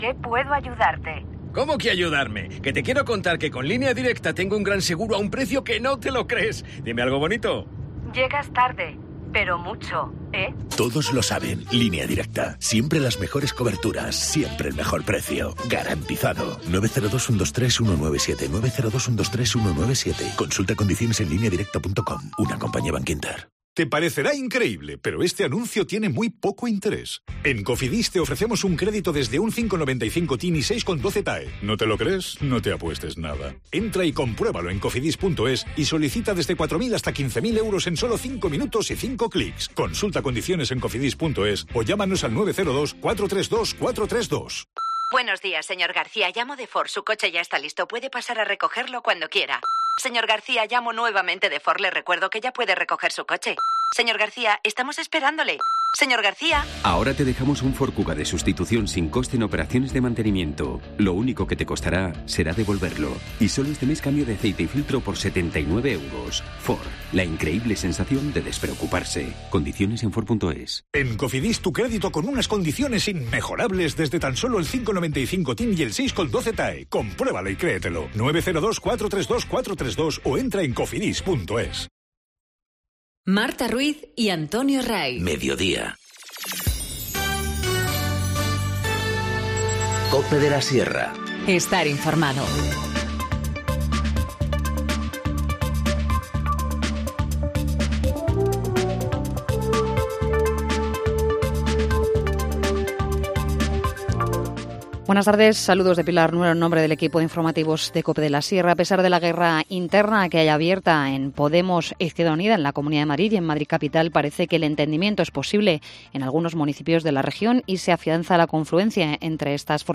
Informativo Mediodía 21 enero- 14:20h